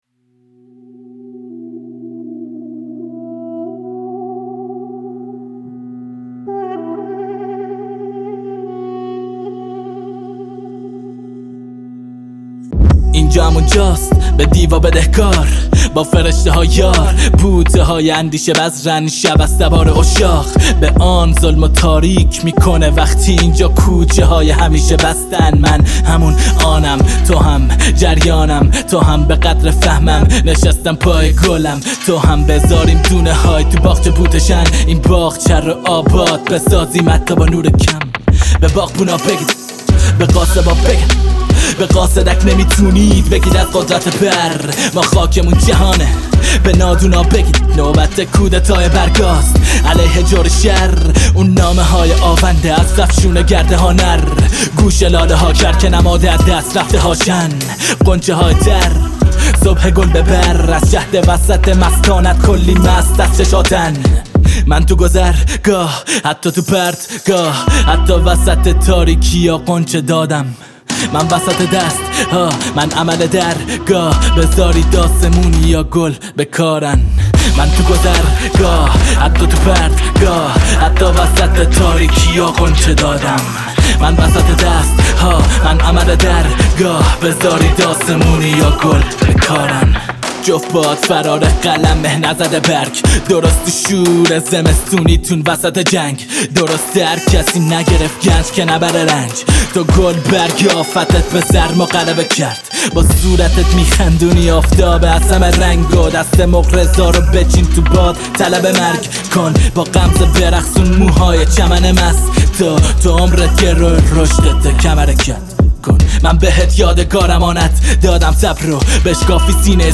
همه ی اخبار ها و حاشیه های رپ فارسی